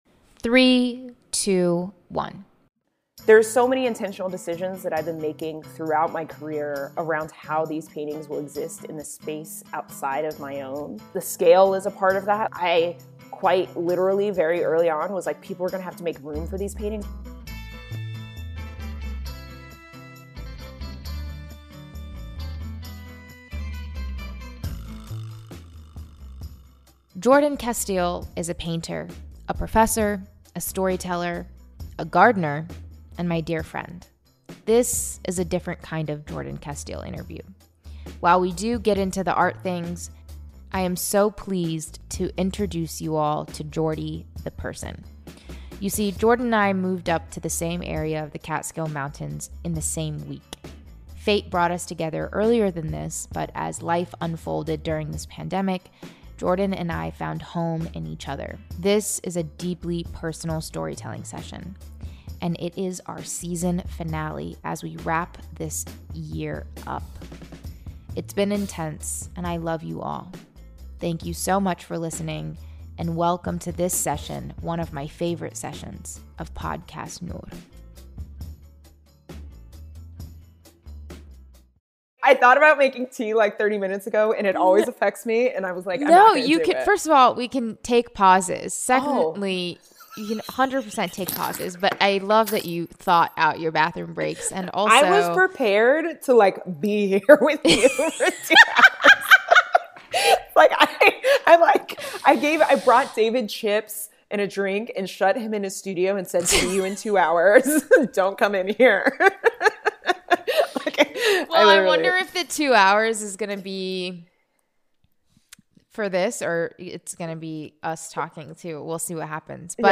For our #23 Jordan Episode, I interview... Jordan!